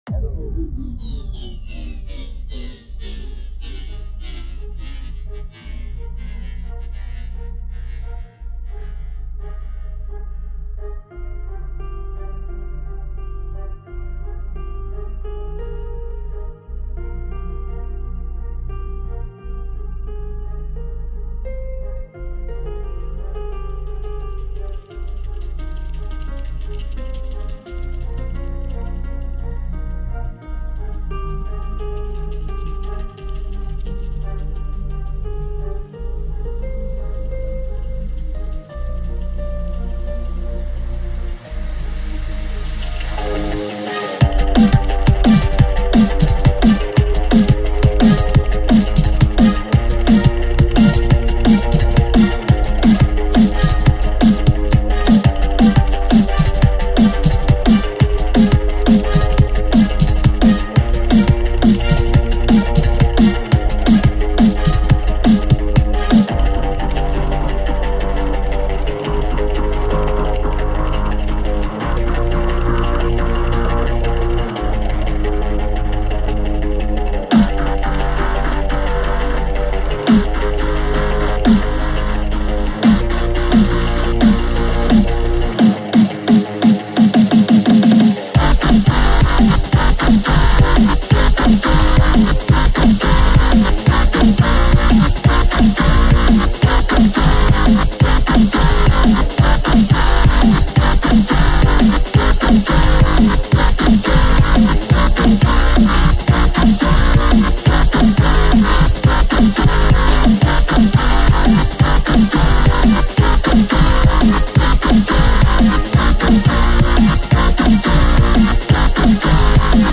Edit: Yes, that is really me singing.